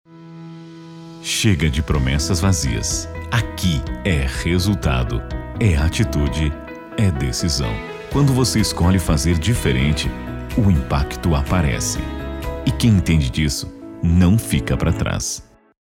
Interpretado:
Padrão: